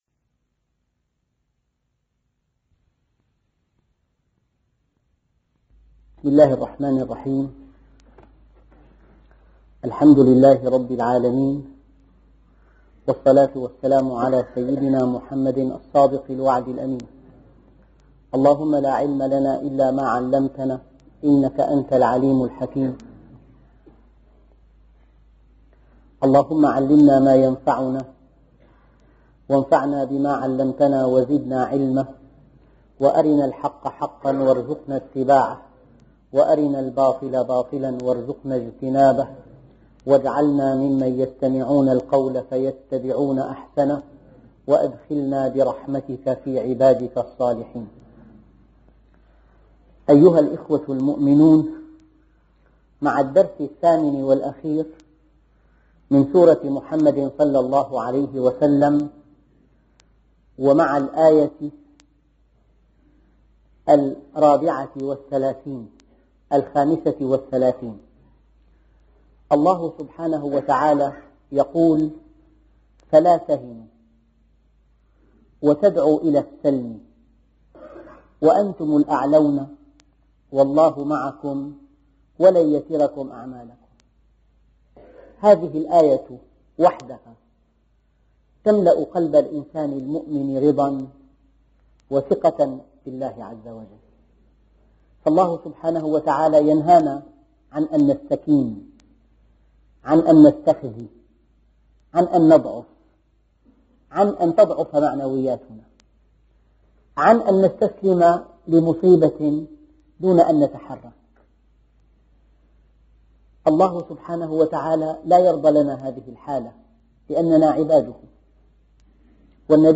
أرشيف الإسلام - أرشيف صوتي لدروس وخطب ومحاضرات د. محمد راتب النابلسي